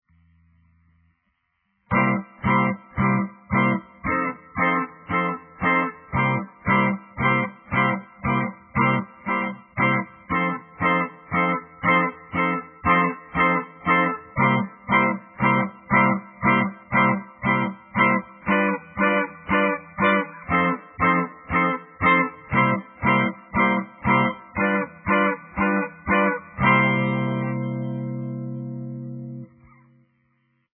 12 Bar Blues in C
12 bars in C